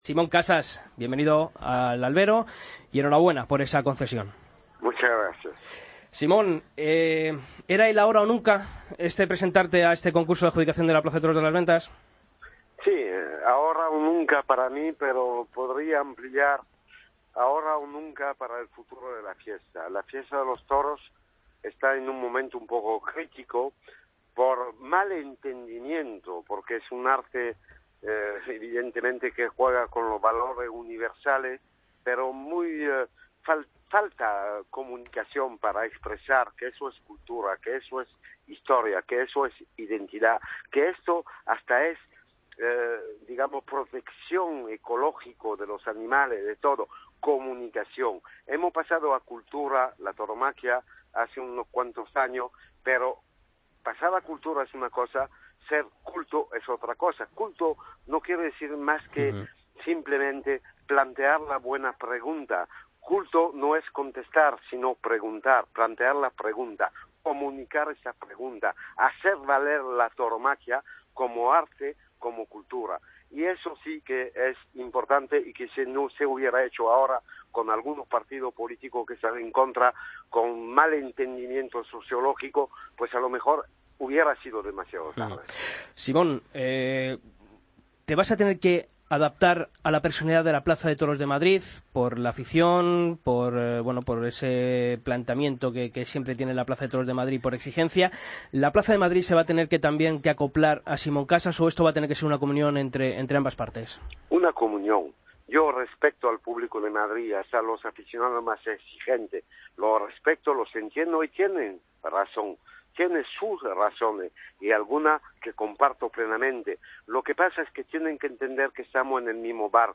Escucha la entrevista completa a Simón Casas en El Albero